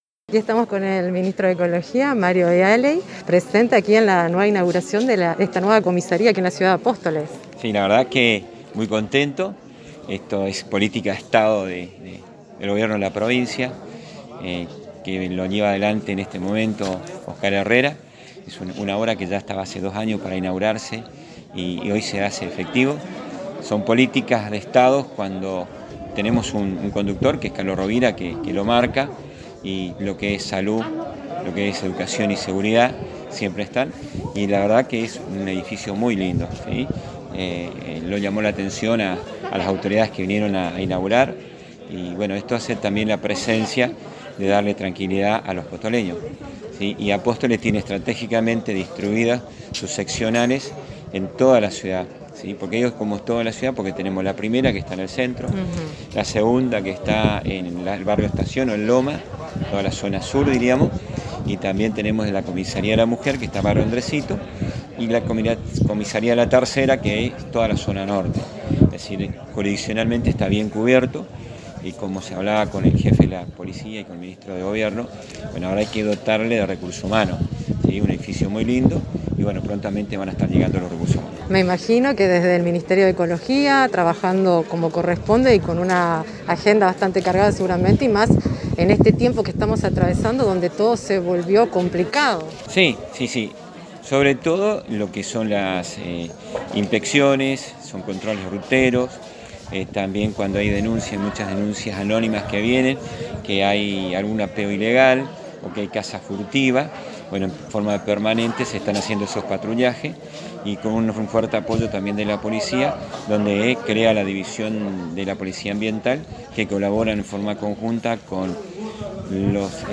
Así lo expresó el Ministro de Ecología Mario Vialey en exclusiva para ANG y el informativo de Radio Cadena Lider Misiones 93.7 LLR 623, quien estuvo presente en la inauguración de la nueva Comisaria tercera UR- VII.